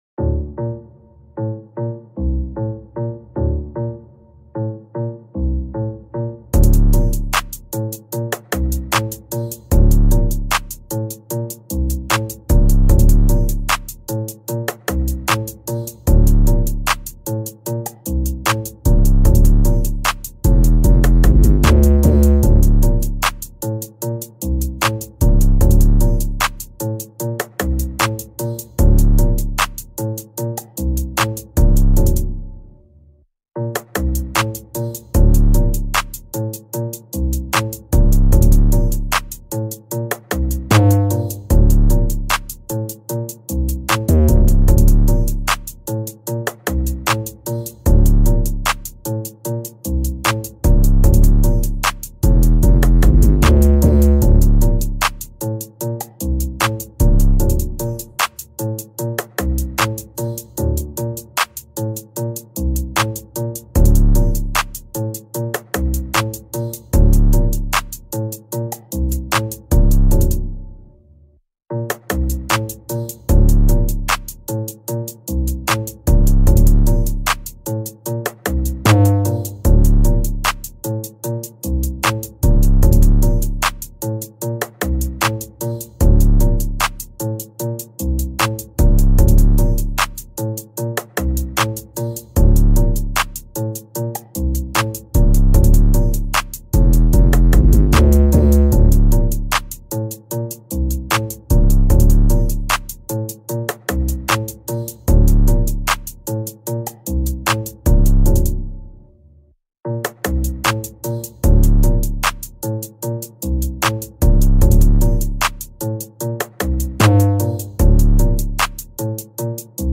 This is the remix